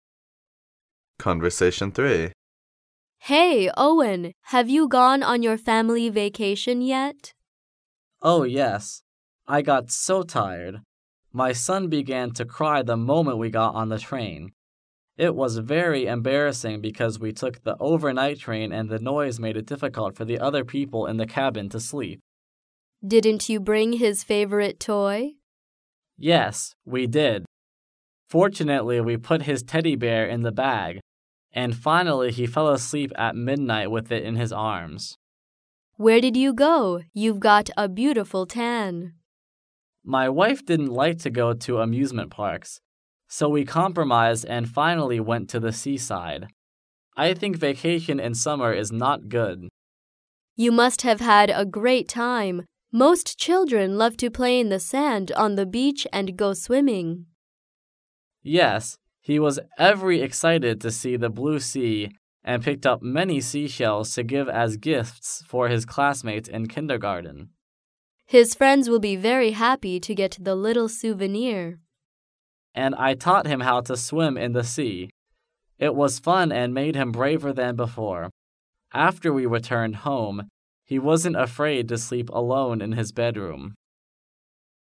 Conversation 3